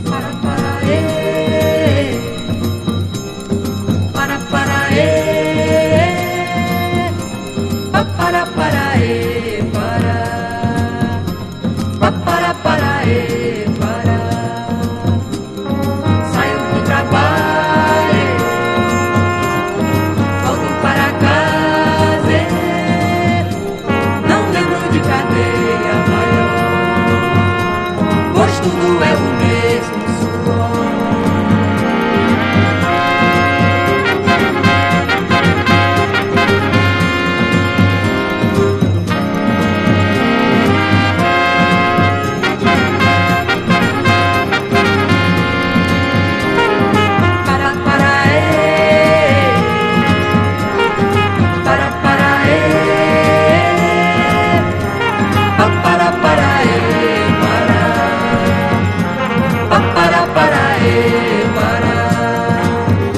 WORLD / LATIN / BOSSA NOVA
素晴らしいストリングス・アレンジに魅了されるボサノヴァ/ラテン・ヴォーカル大傑作！ メキシコの女性S.S.W.